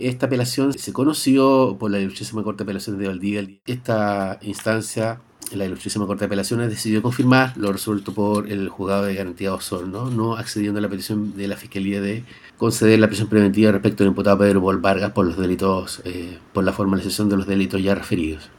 No obstante, la Corte de Apelaciones de Valdivia resolvió mantener las cautelares menos gravosas para el acusado, confirmó el fiscal Jorge Münzenmayer.